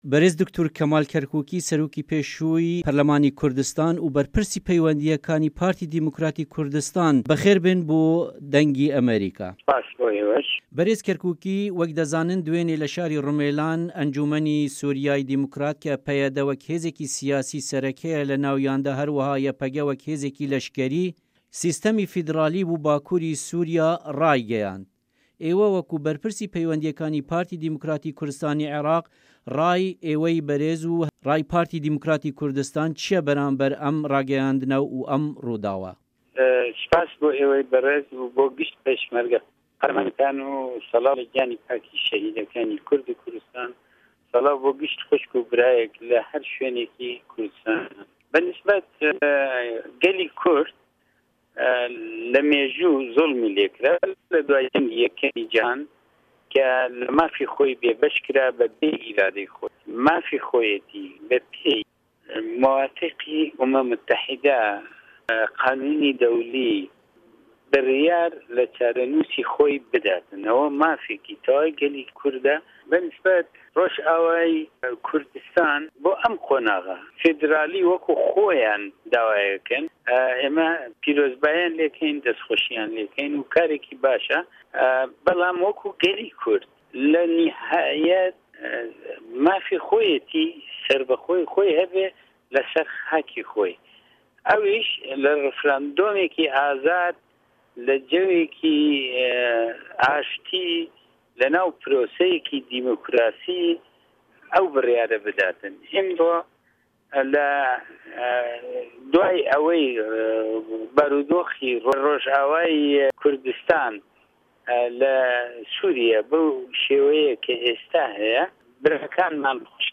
Hevpeyvîn li gel Dr.Kemal Kerkukî